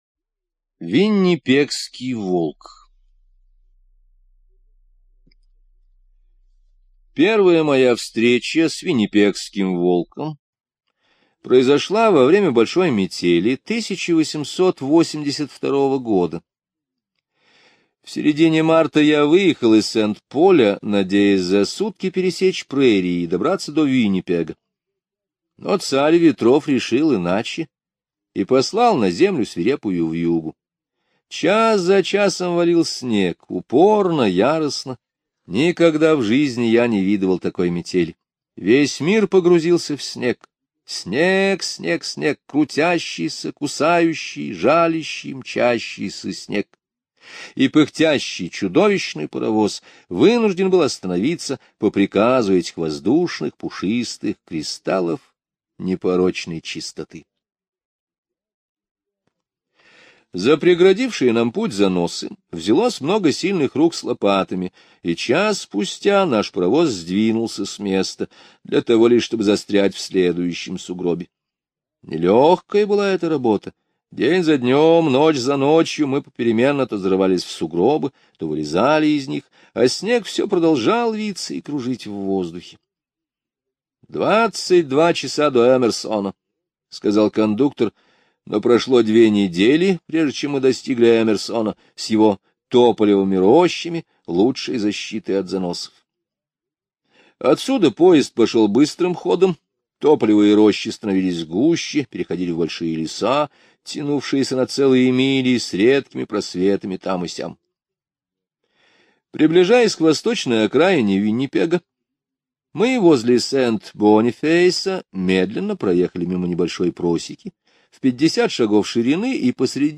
Виннипегский волк - аудио рассказ Сетона-Томпсона Э. Рассказ о трогательной дружбе мальчика и волка, выросшего на цепи.